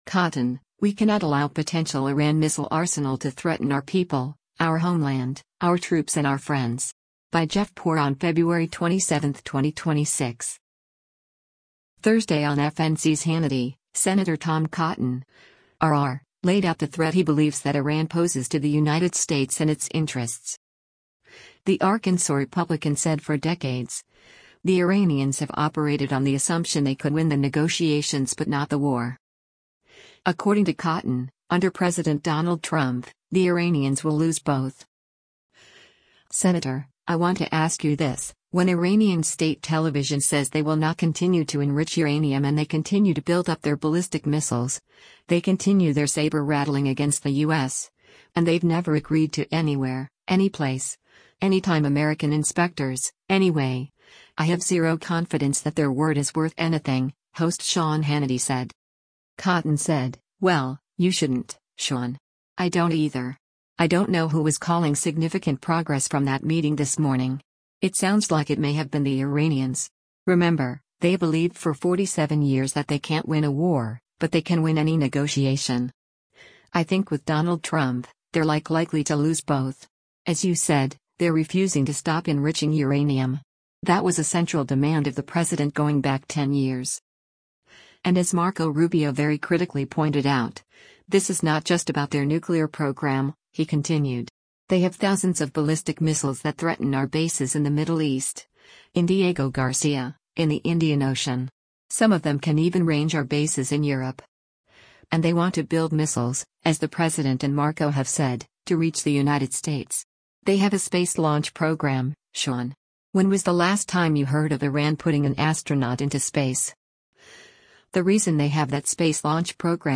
Thursday on FNC’s “Hannity,” Sen. Tom Cotton (R-AR) laid out the threat he believes that Iran poses to the United States and its interests.